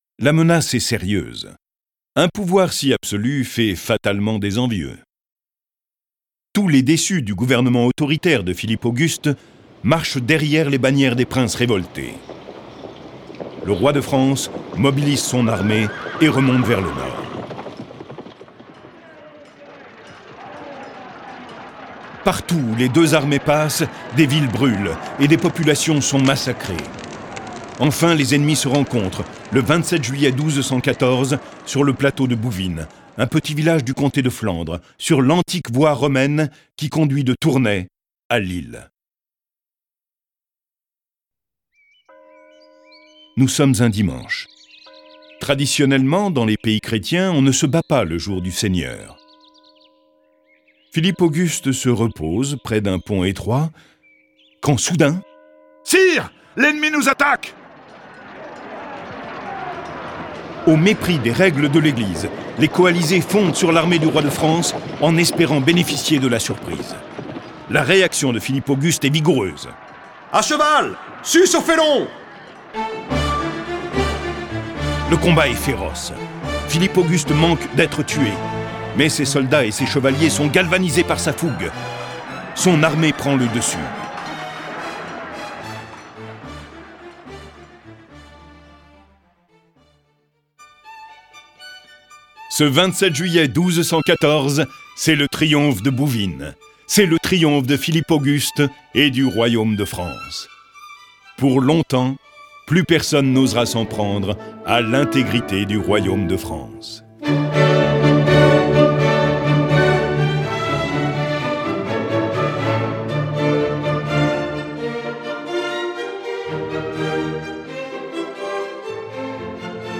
Cette version sonore de ce récit est animée par dix voix et accompagnée de plus de trente morceaux de musique classique.